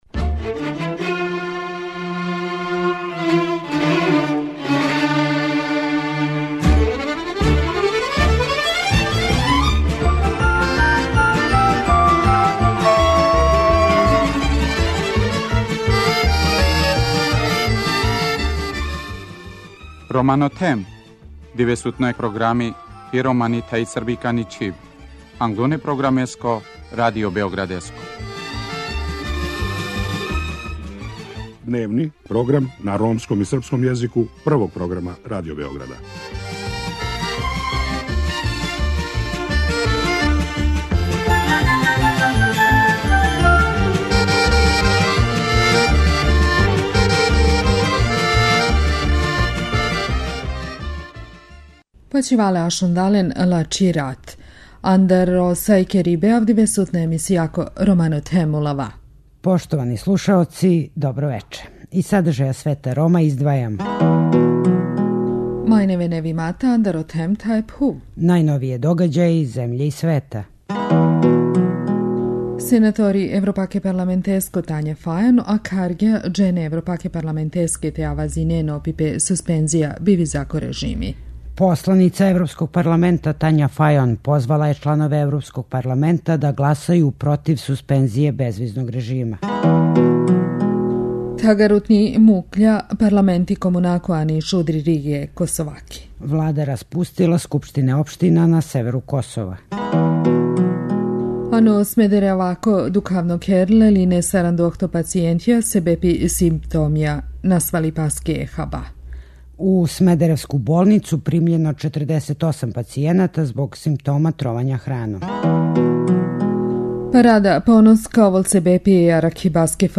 Гост вечерашње емисије 'Романо Тхем' је Горан Башић, заменик Заштитника грађана који говори о резултатима примене Стратегије за унапређивање живота Рома.